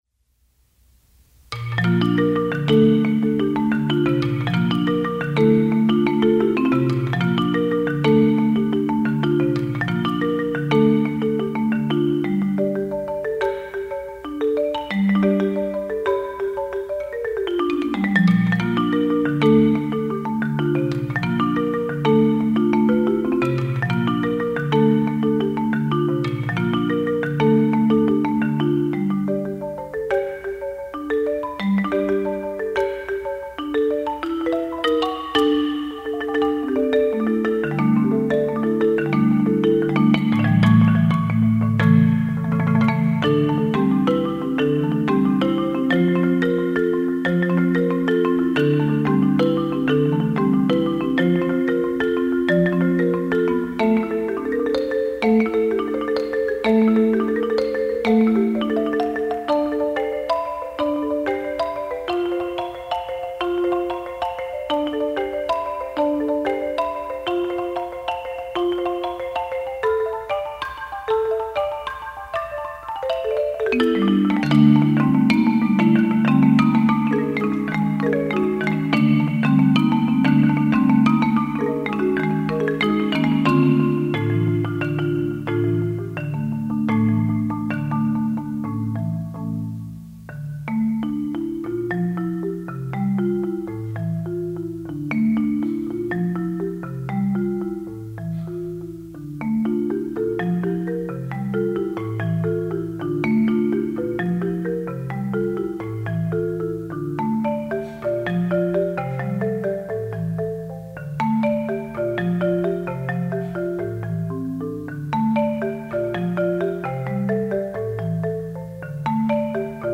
Voicing: Marimba Unaccompanied